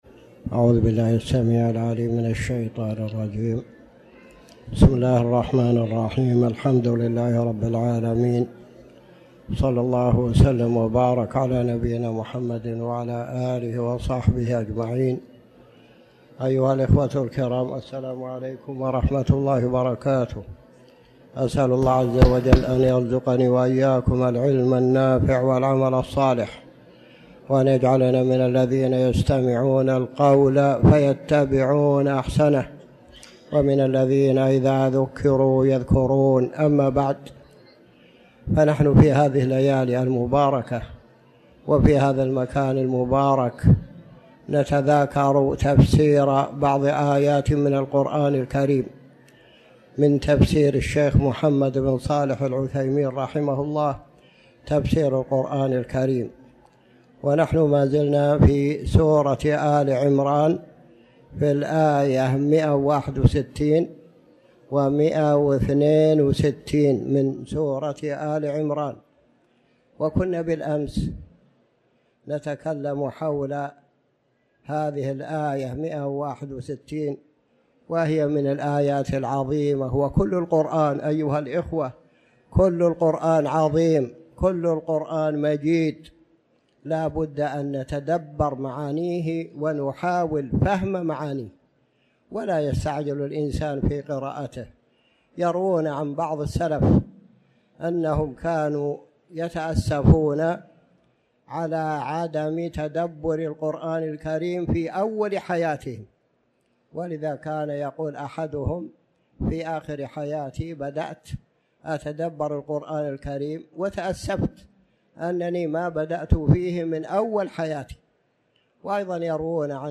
تاريخ النشر ١٠ ربيع الأول ١٤٤٠ هـ المكان: المسجد الحرام الشيخ